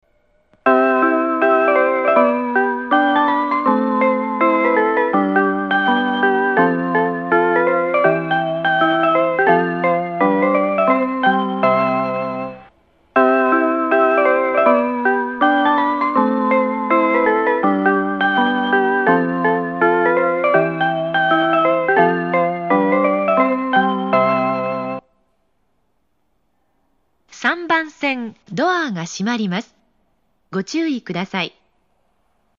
北千住３番線 発車